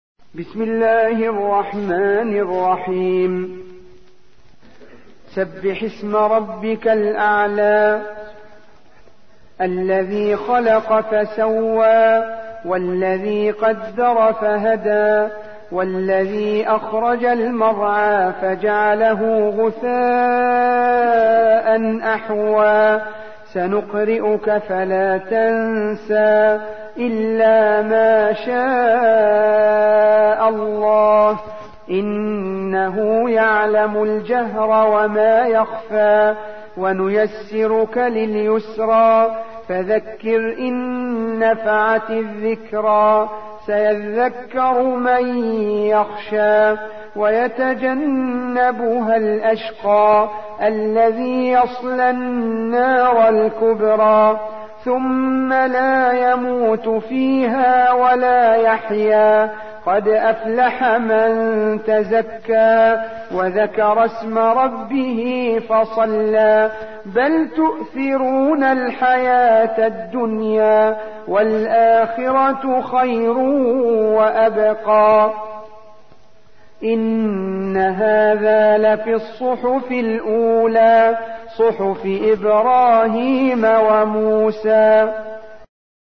سورة الأعلى / القارئ